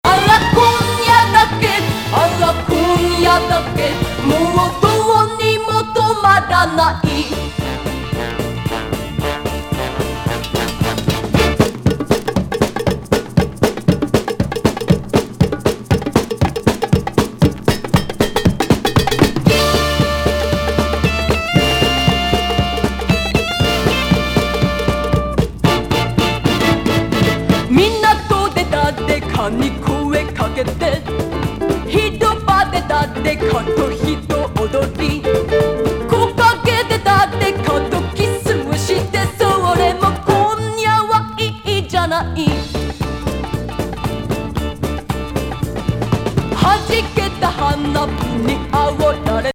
ズンドコ・パーカッシヴ歌謡クラシック。トライバル・ブレイク!